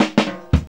FILL 2   114.wav